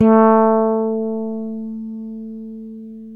Index of /90_sSampleCDs/Roland L-CDX-01/BS _Jazz Bass/BS _Warm Jazz